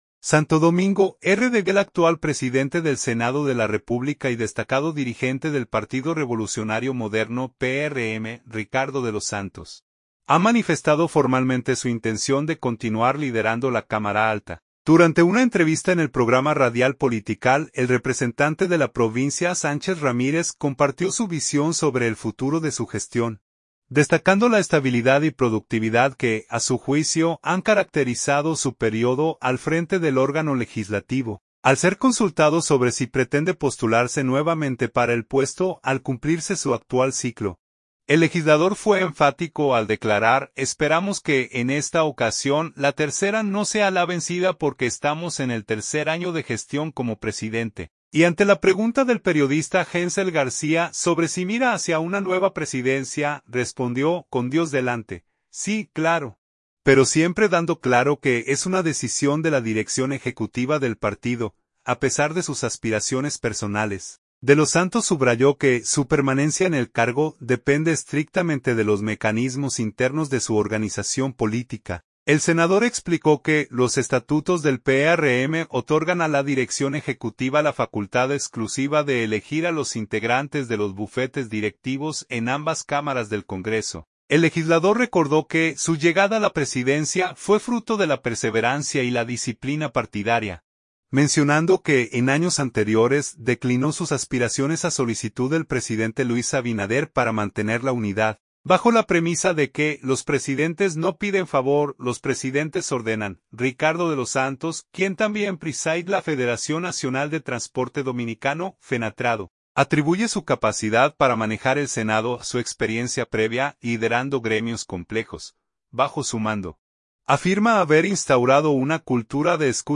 Durante una entrevista en el programa radial Politikal, el representante de la provincia Sánchez Ramírez compartió su visión sobre el futuro de su gestión, destacando la estabilidad y productividad que, a su juicio, han caracterizado su periodo al frente del órgano legislativo.